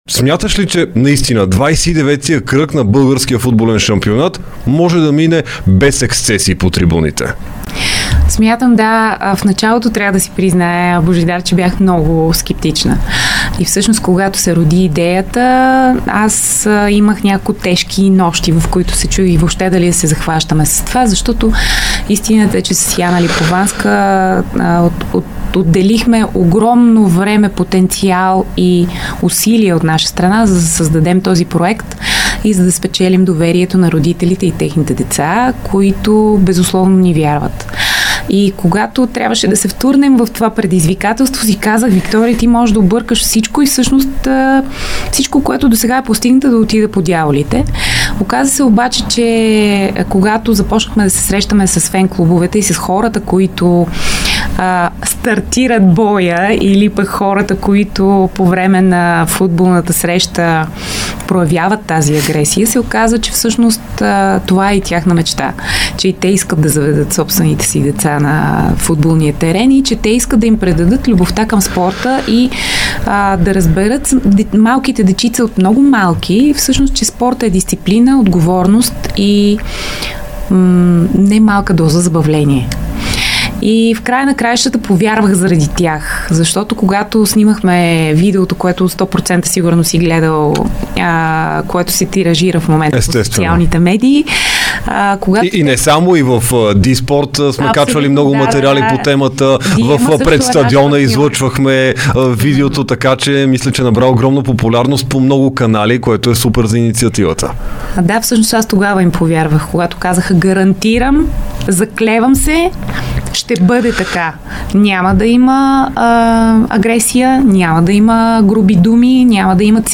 даде специално интервю за Дарик и dsport.